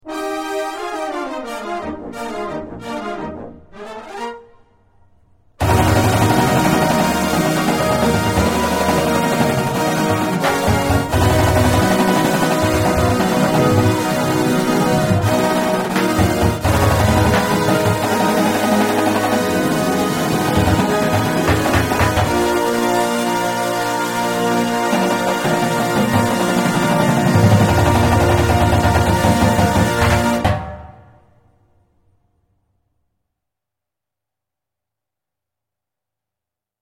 Here's the version with brass included.